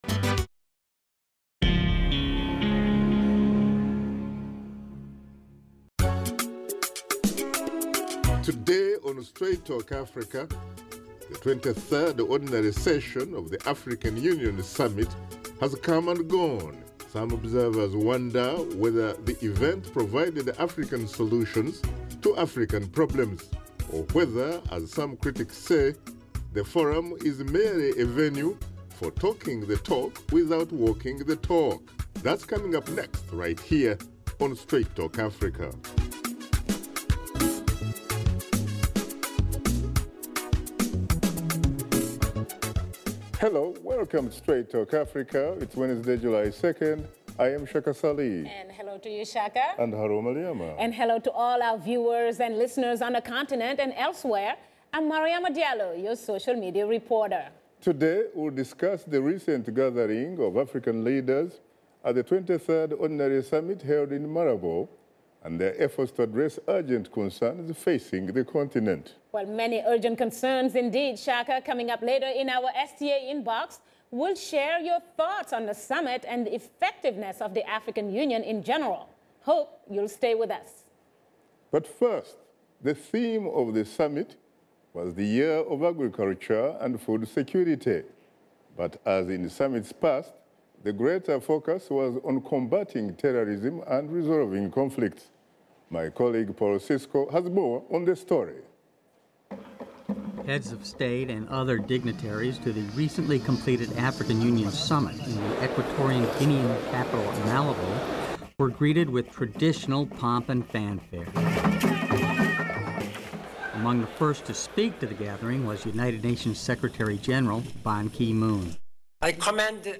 Host Shaka Ssali and his guests discuss the latest developments from the African Union Summit.